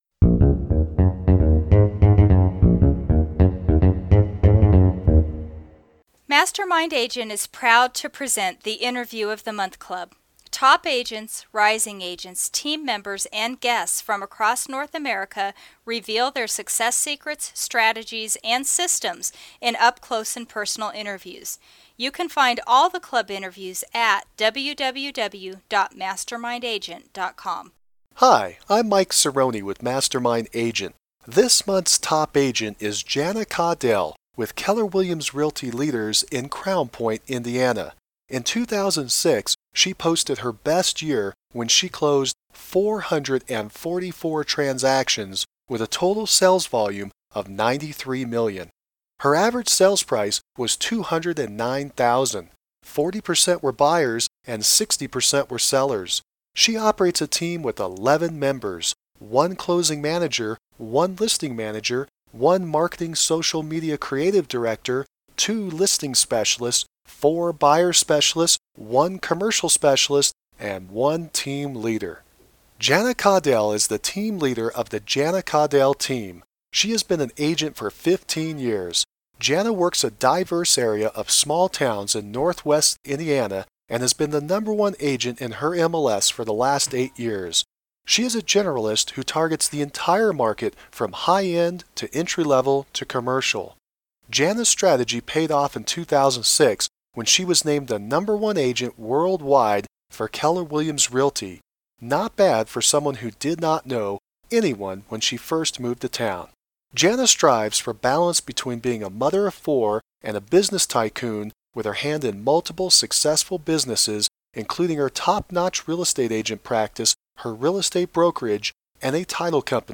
June 2011 Top Agent Interview with